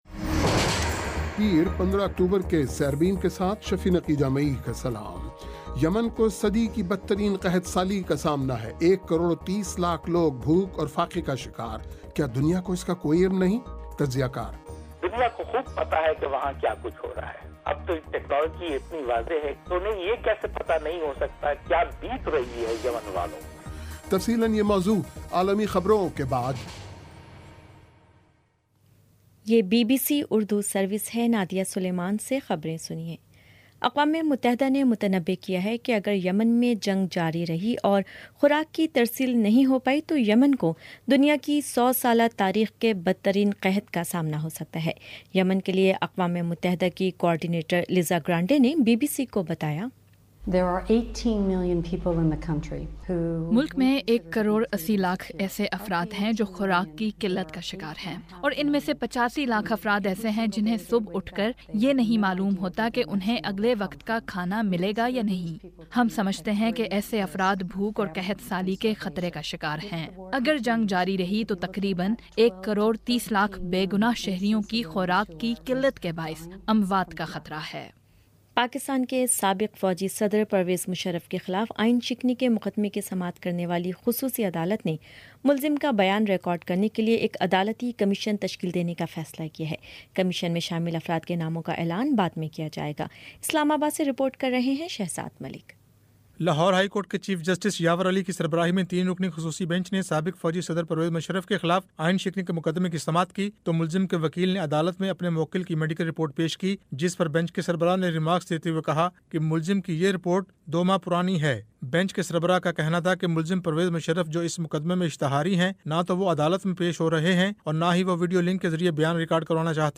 پیر 15 اکتوبر کا سیربین ریڈیو پروگرام